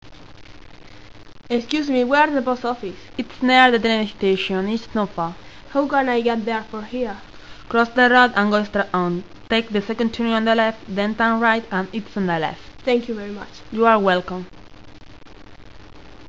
Everyday conversations
Una chica se dirige a otra en la calle y le pregunta algo.